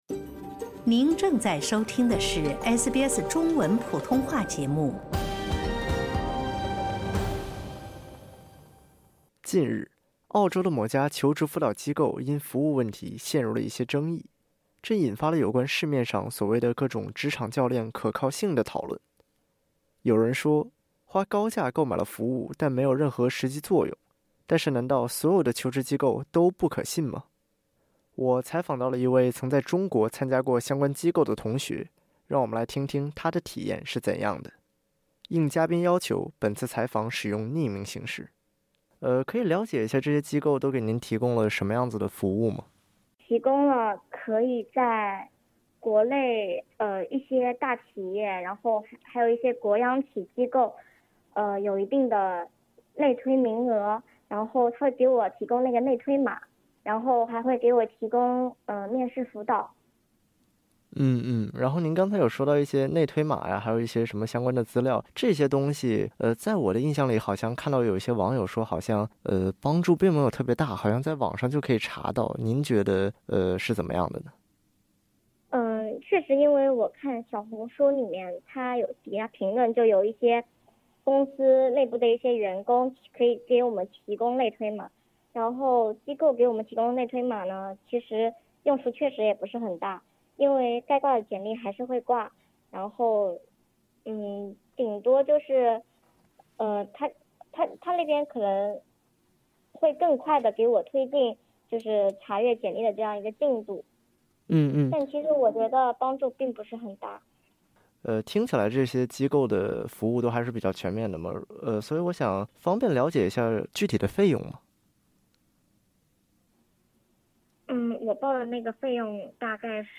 在采访中，本台记者联系到了一位曾在中国参加过类似求职辅导课程的同学。
（由于嘉宾要求，本次采访采用匿名形式）